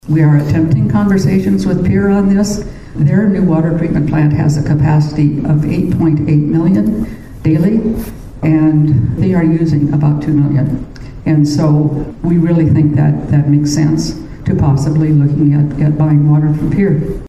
During the Fort Pierre Development Corporation annual meeting last week Mayor Gloria Hanson says the city is starting those conversations again…